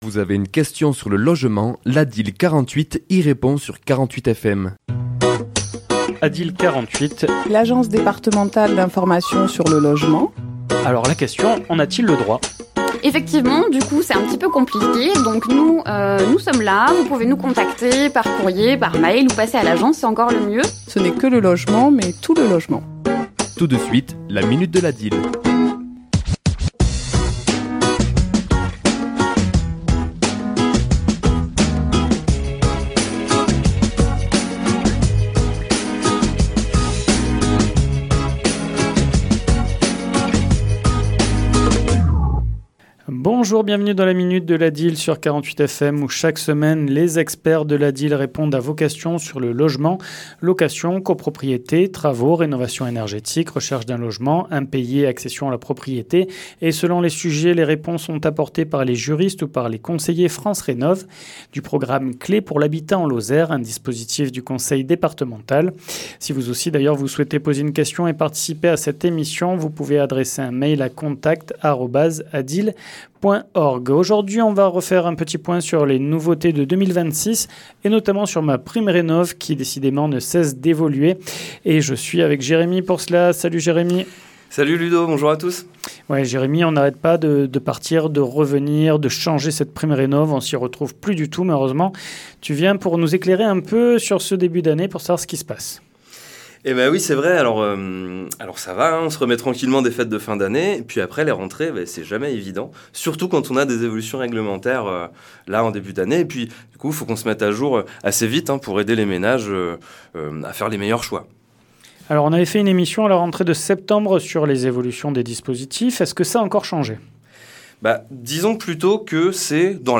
Chronique diffusée le mardi 27 janvier à 11h et 17h10